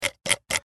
Звуки клика мышкой
На этой странице собраны звуки кликов компьютерной мыши — от одиночных щелчков до быстрых последовательностей.